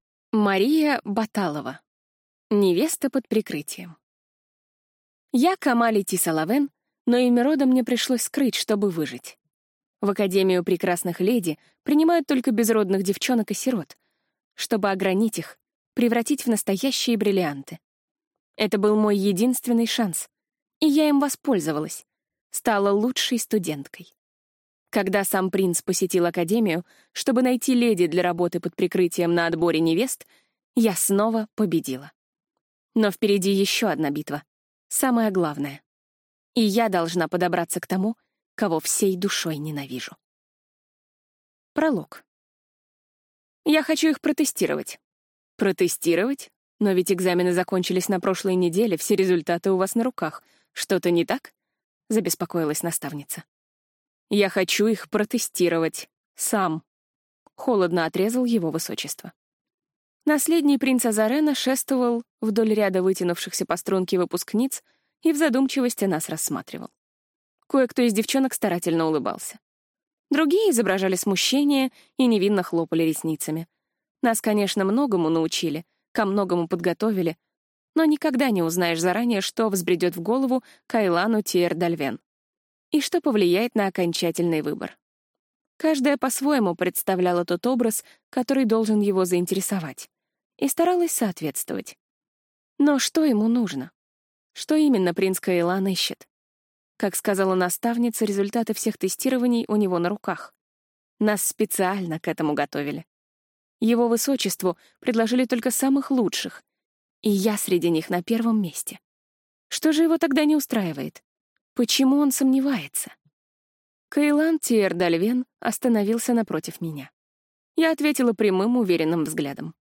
Аудиокнига Невеста под прикрытием | Библиотека аудиокниг